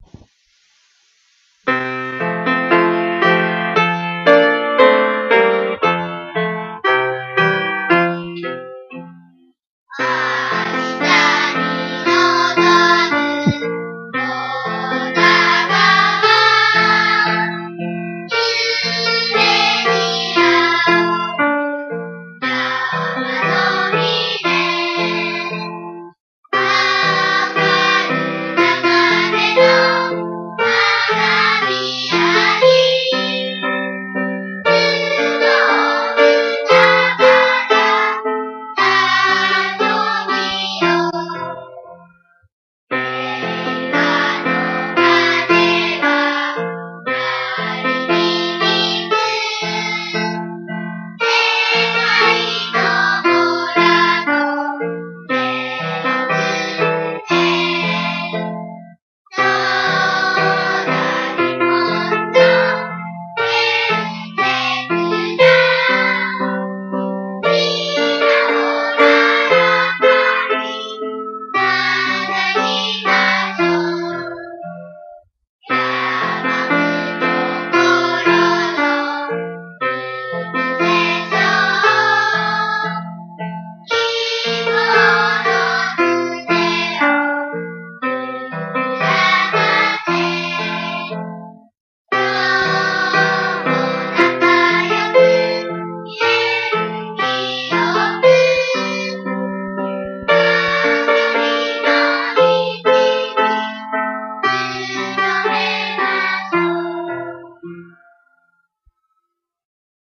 校歌（児童合唱）